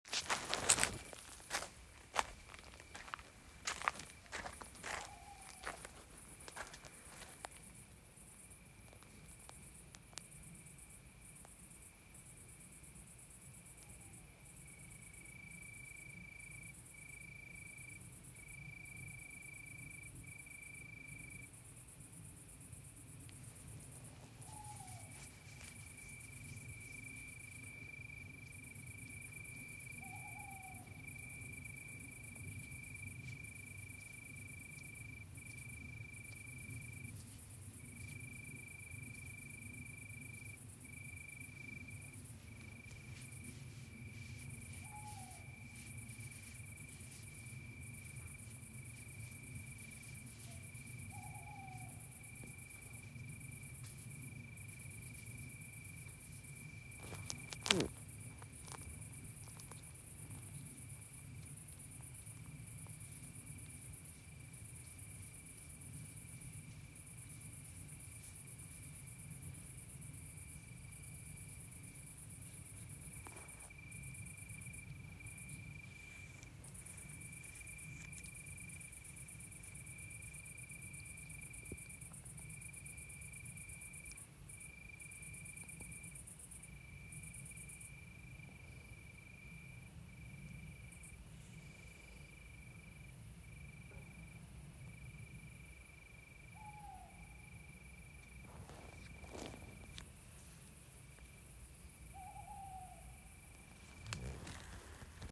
Grillengesang von La Bourdeneuve